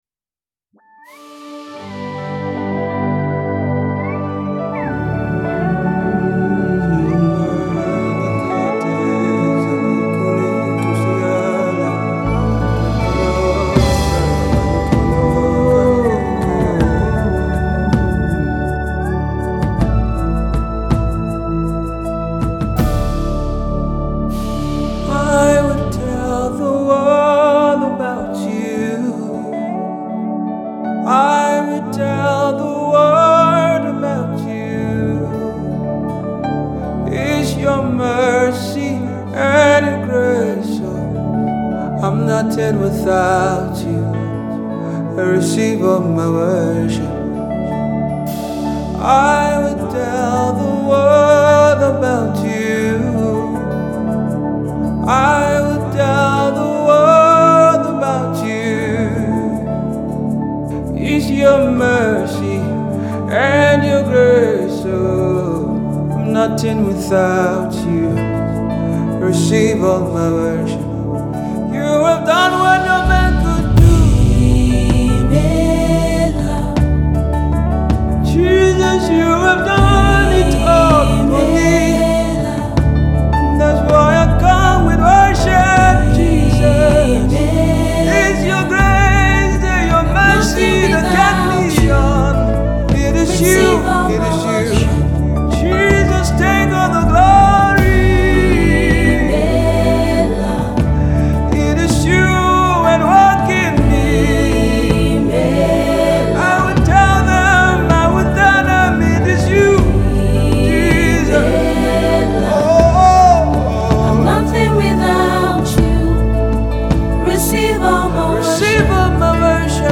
He drops yet another Spirit inspired song of gratitude
With this song you can’t help but worship.